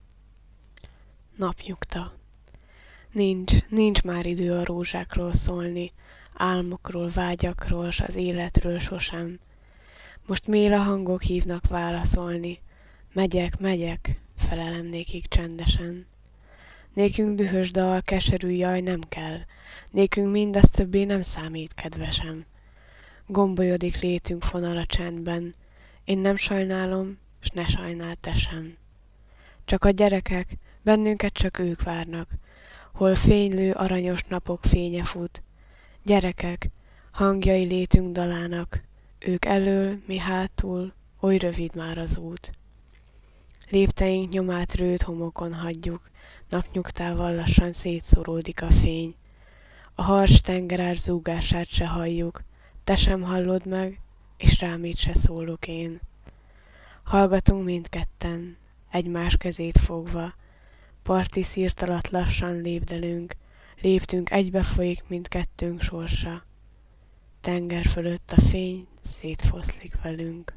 Recitado